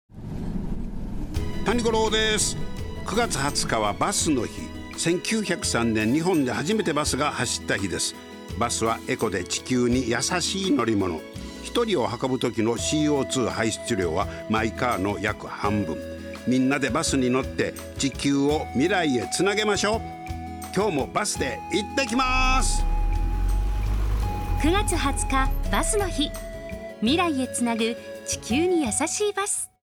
ラジオCM音声はこちら ニュース一覧